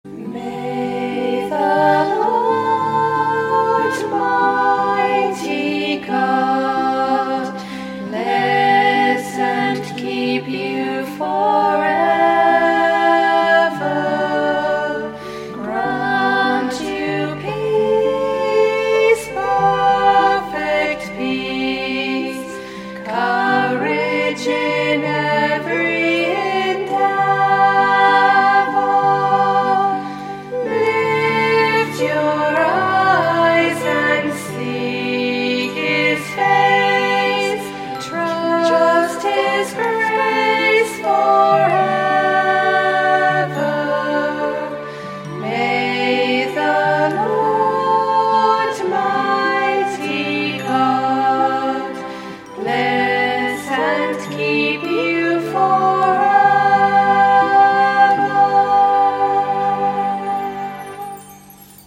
lead vocals.
harmony vocals
keyboards and recording production.